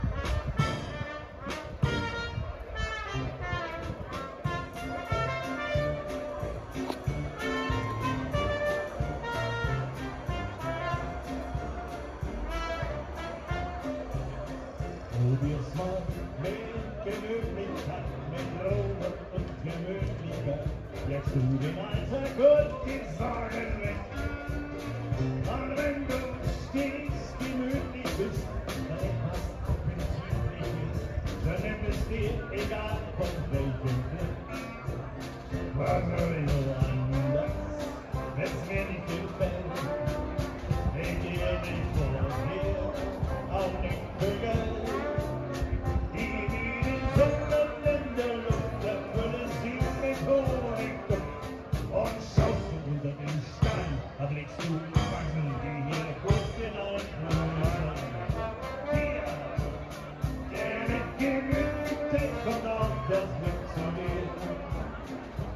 Juli 2025, im doppelten Sinne: Bei sonnigem Wetter verwandelte der Jazz-Frühschoppen den Mühlenanger in eine lebendige Bühne voller Musik und Lebensfreude.
Die Waiting Room Jazzband aus Herzberg begeisterte weit über 150 Besucher mit einer mitreißenden Mischung aus Dixieland, Jazz und Blues. Die lebendigen Improvisationen und die gute Laune der Musiker sorgten für eine ausgelassene Stimmung unter den zahlreichen Besuchern.